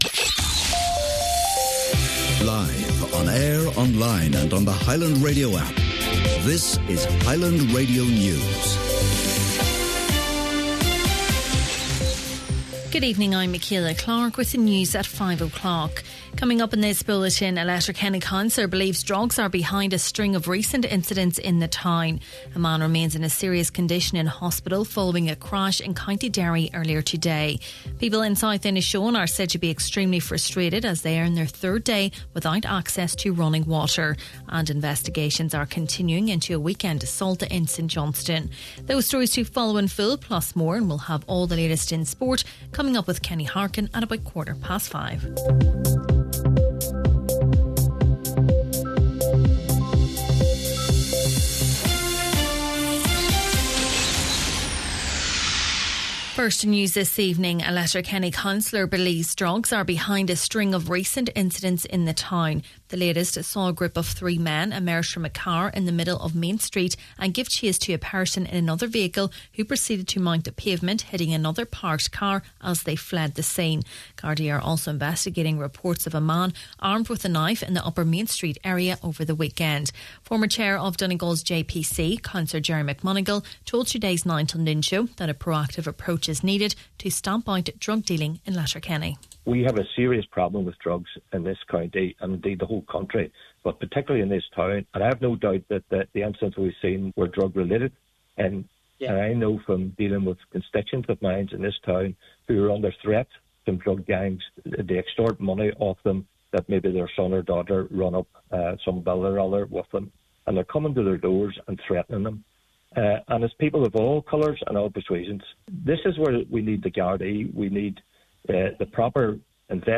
Main Evening News, Sport and Obituaries – Tuesday, August 26th